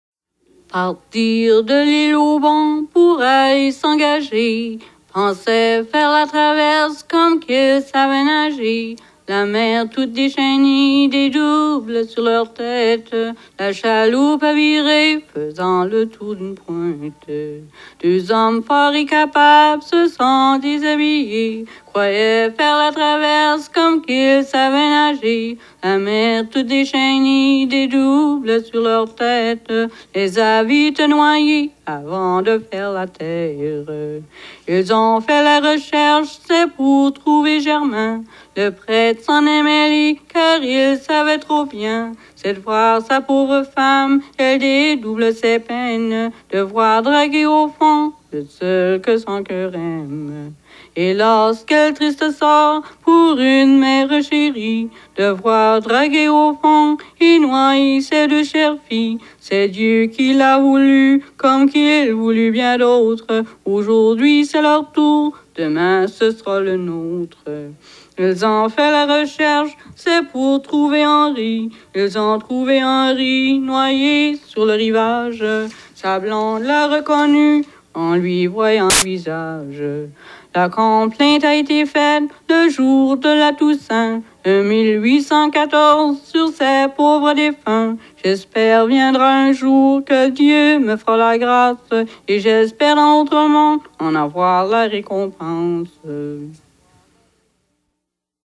enregistré à Val-Comeau, Sheila, co. Gloucester, Nouveau-Brunswick, le 15 août 1976
Genre strophique